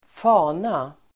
Uttal: [²f'a:na]